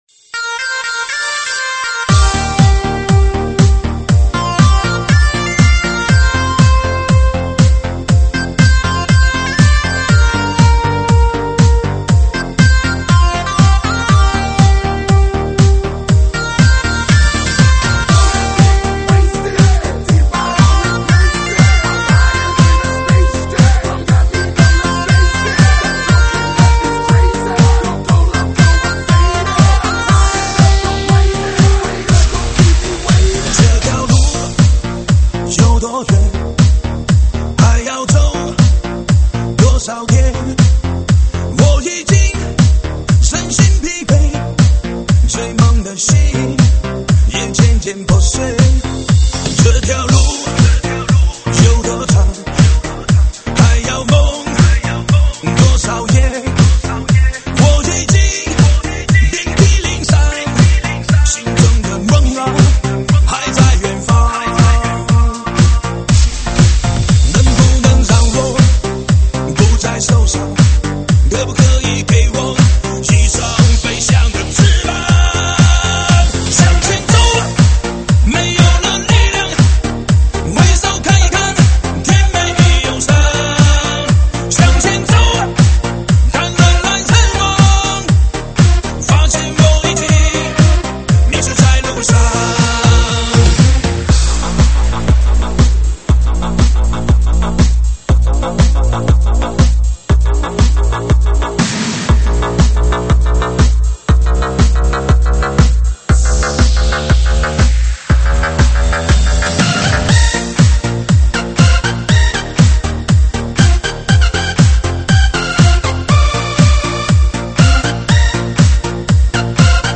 3D全景环绕